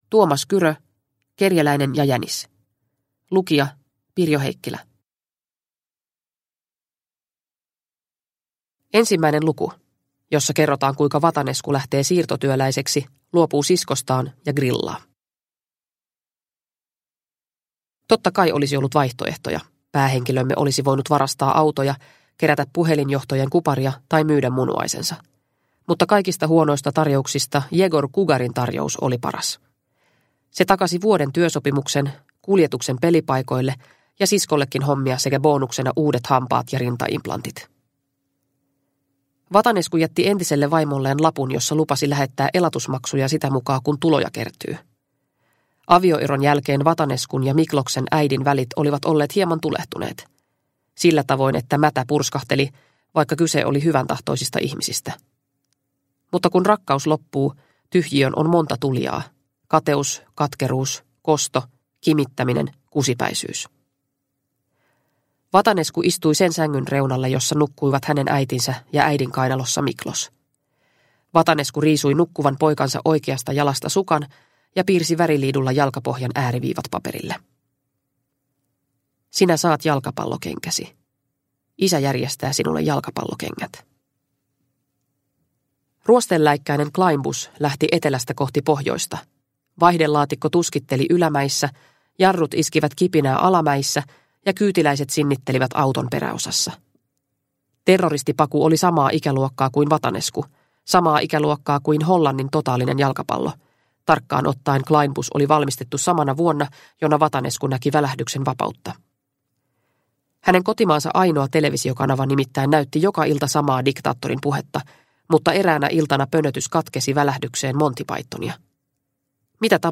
Kerjäläinen ja jänis – Ljudbok